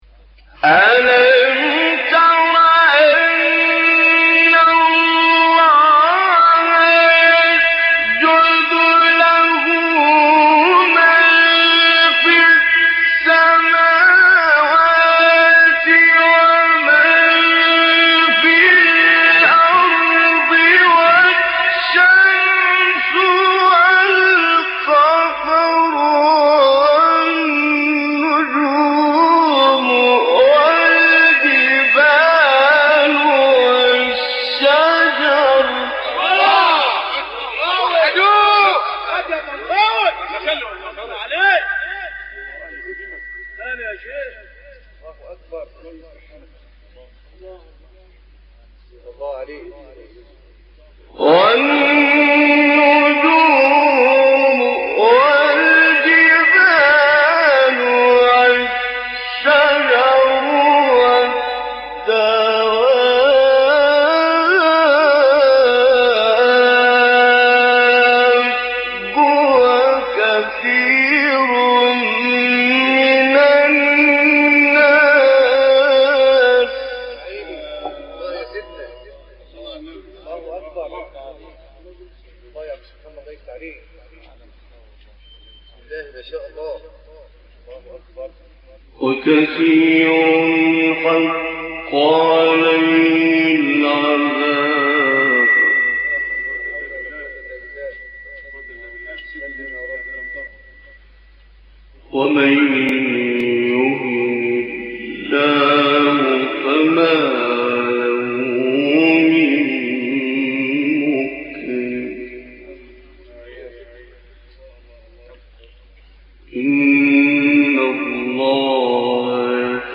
تلاوت آیه 18 سوره حج توسط استاد طنطاوی | نغمات قرآن | دانلود تلاوت قرآن